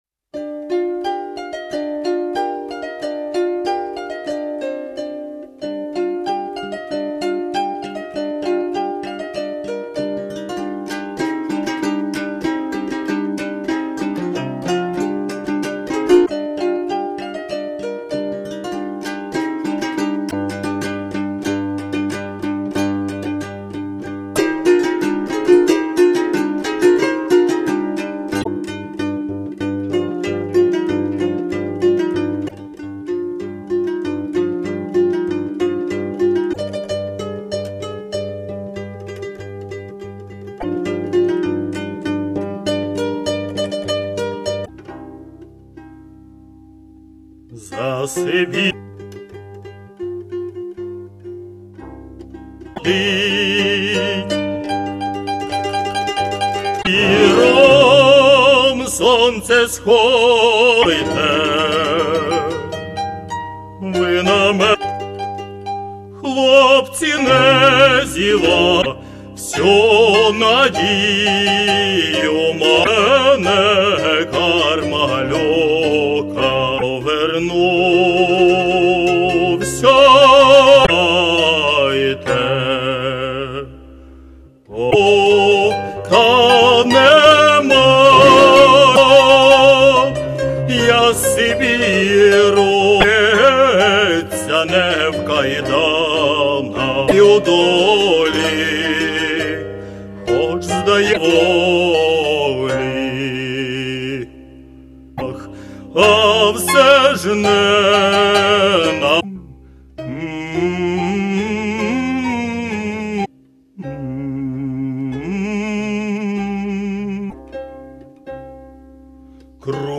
15-Про_Кармелюка.Народна_пісня_обробці_Г.Хоткевича.mp3